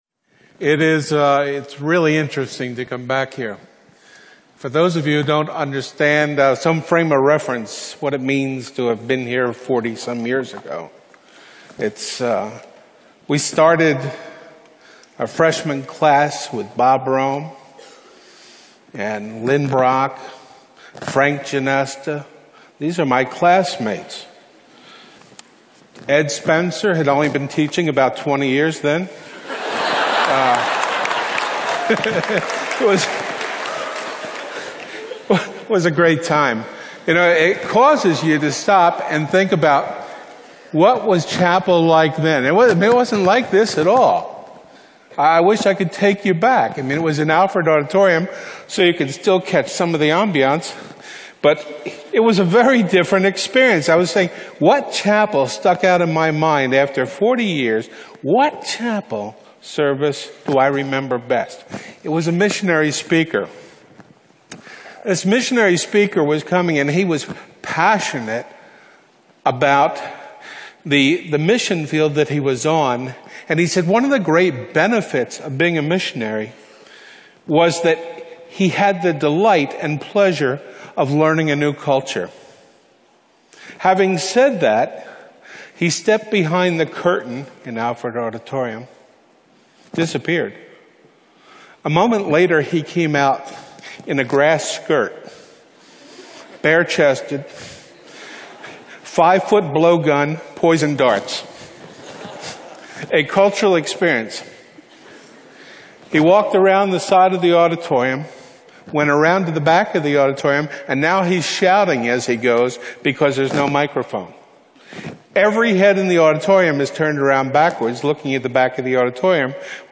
Alumni Week 2008.